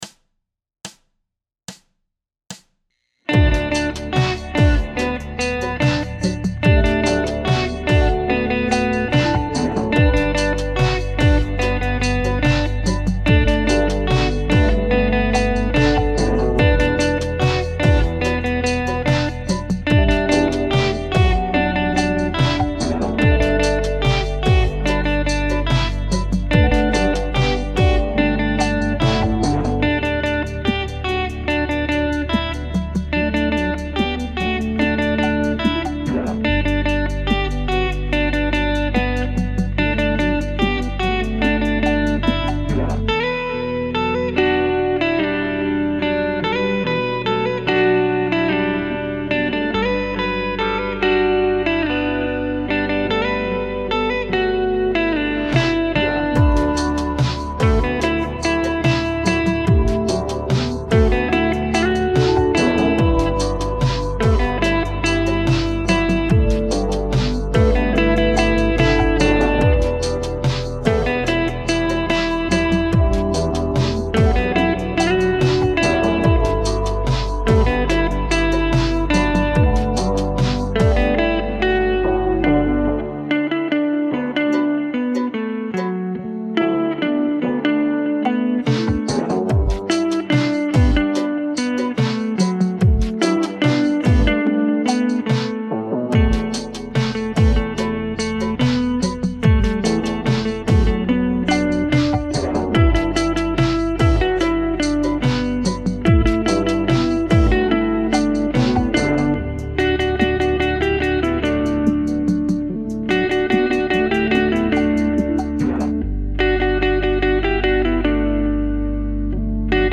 Slow-Speed Audio